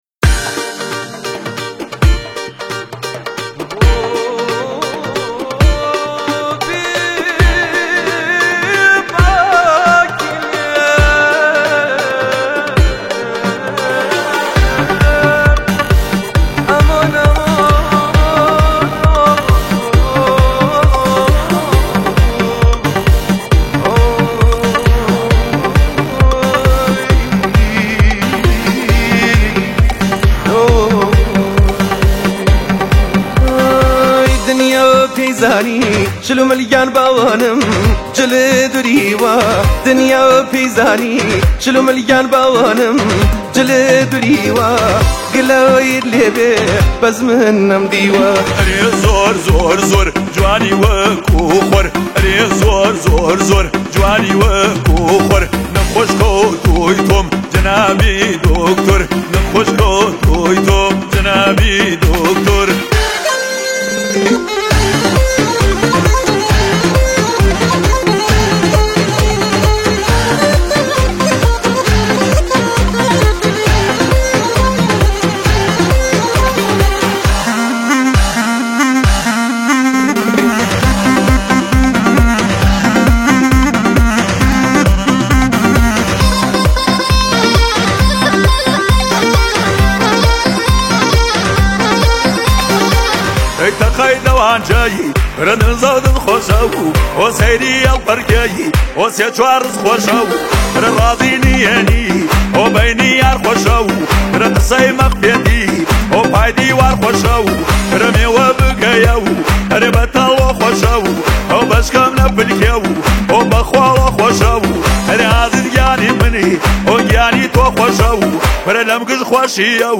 این آهنگ کردی